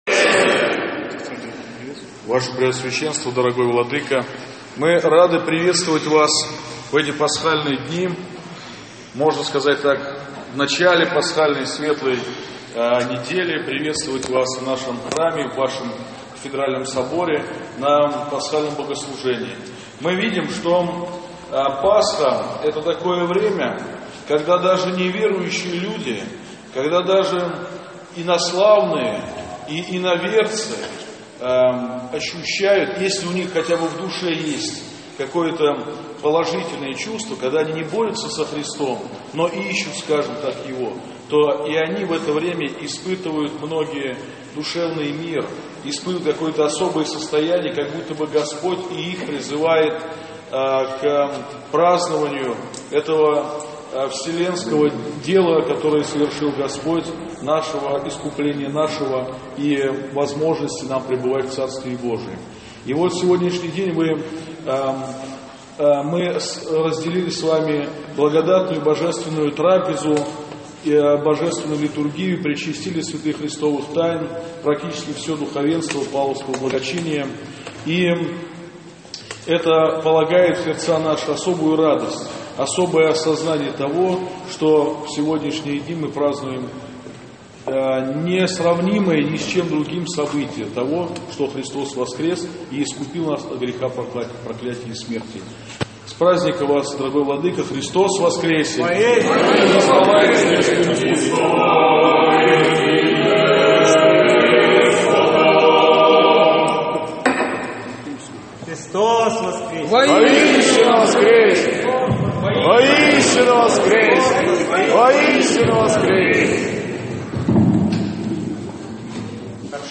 2 мая, в понедельник Светлой Седмицы, епископ Выксунский и Павловский Варнава возглавил Божественную литургию в Вознесенском кафедральном соборе г.Павлово.